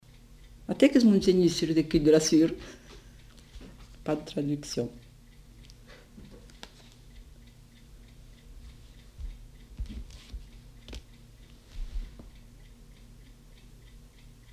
Virelangue